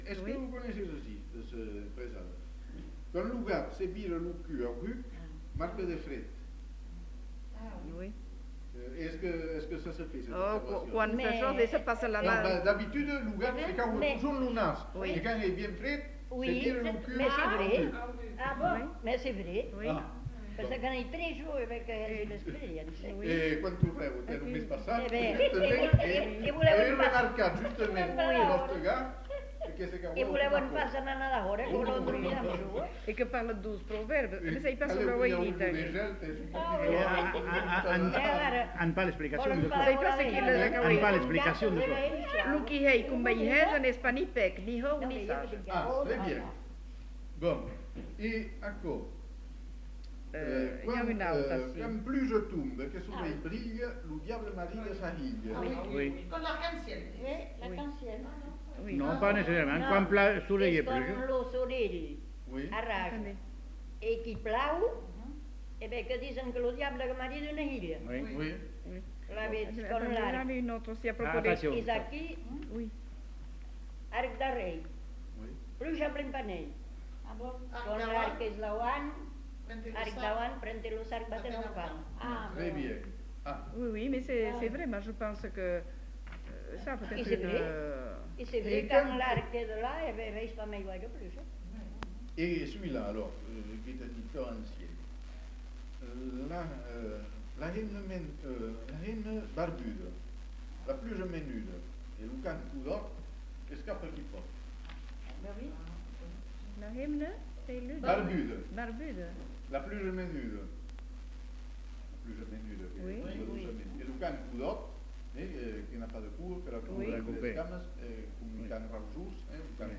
Lieu : Uzeste
Type de voix : voix d'homme ; voix de femme Production du son : récité
Classification : proverbe-dicton